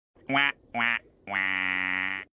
Play, download and share wha wah wah original sound button!!!!
wha-wah-wah.mp3